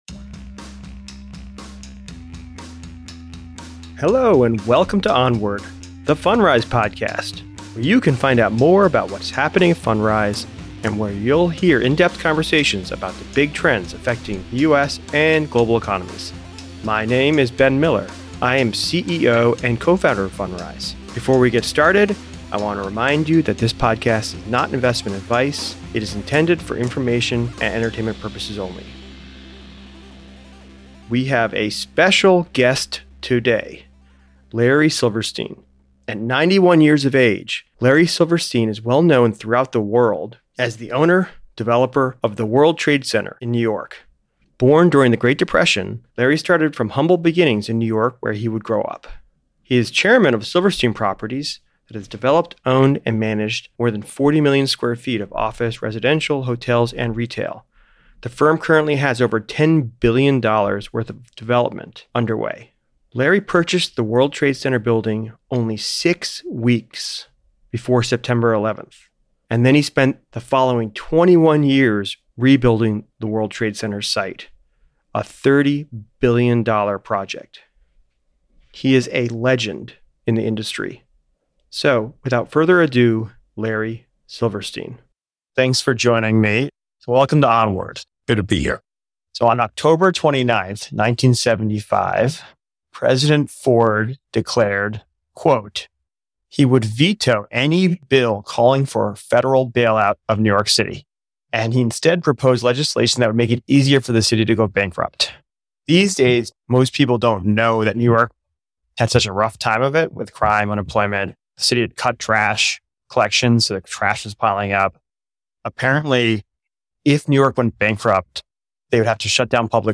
The interview gives us a tour of both Mr. Silverstein’s life and the history of New York, as he recounts his experience investing in a number of influential New York properties.